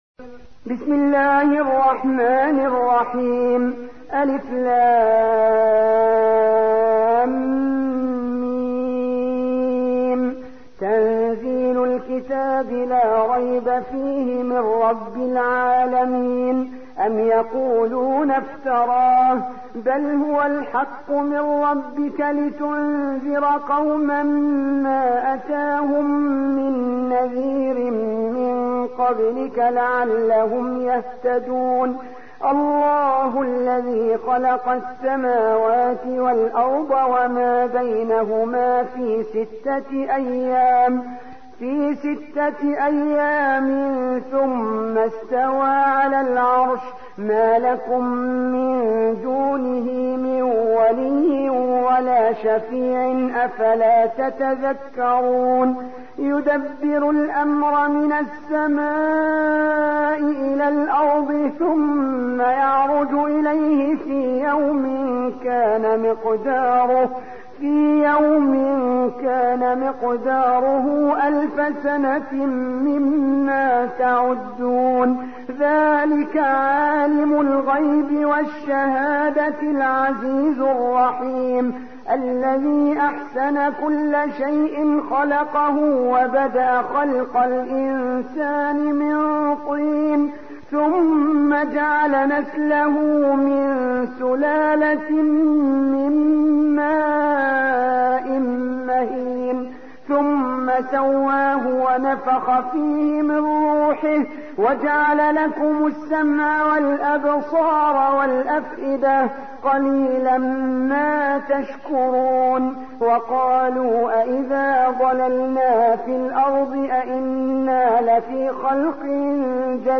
32. سورة السجدة / القارئ